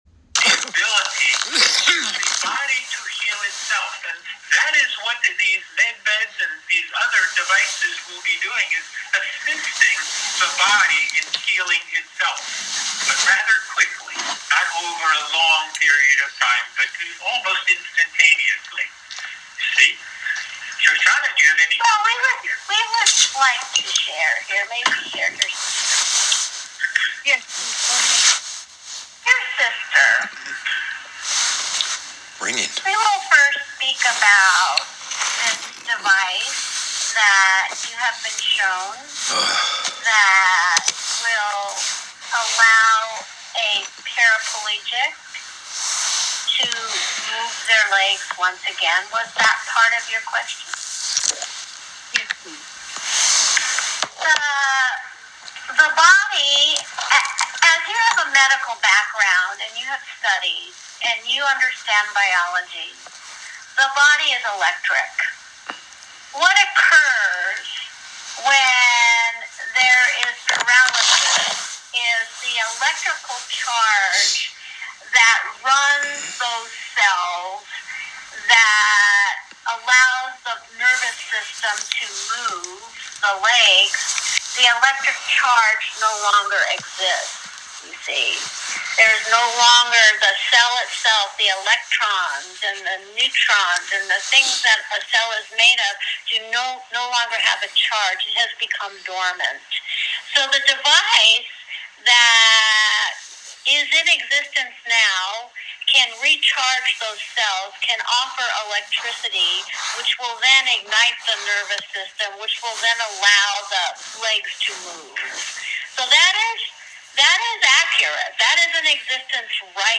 24.12.31 – New Years Q&A Session